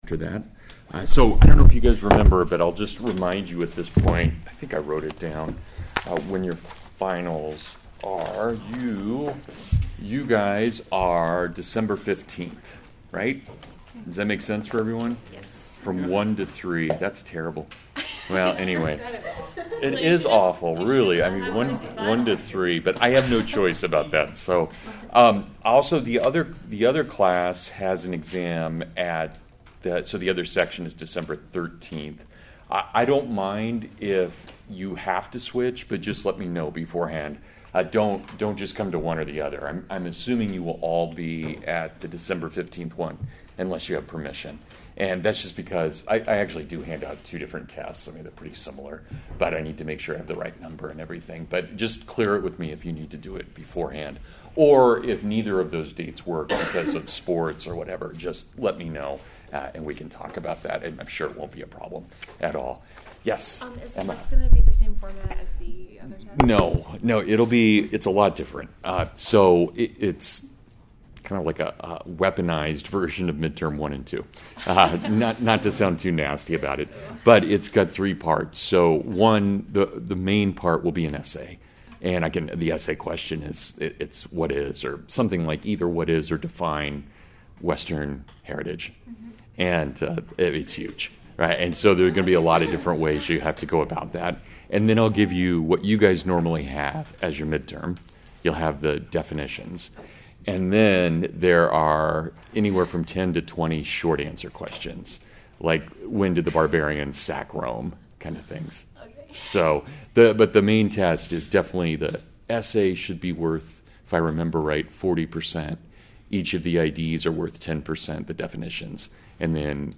The Historical Foundations of Protestantism (FULL LECTURE)